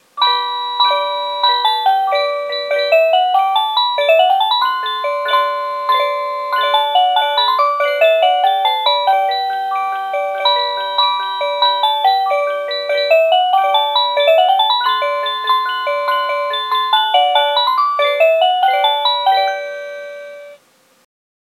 05-Cuckoo-Tune.mp3